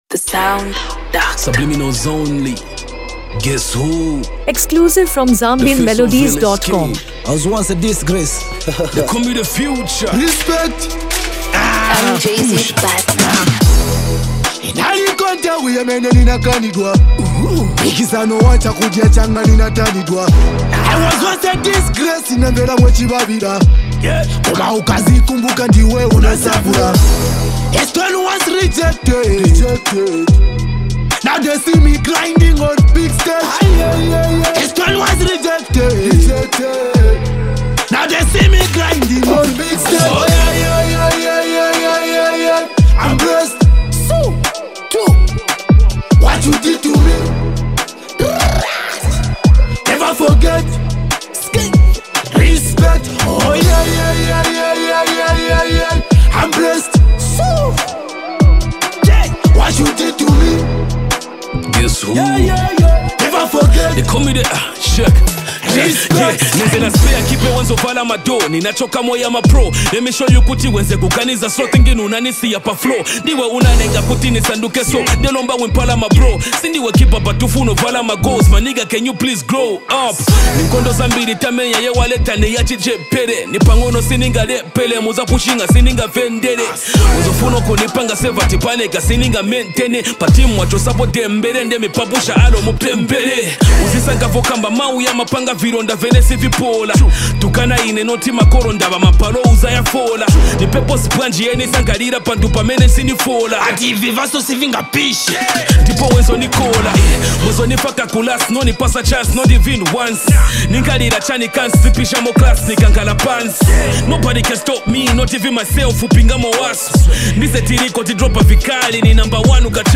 ” a catchy Afro-hip-hop jam about heartbreak and resilience.
Genre: Afro-beats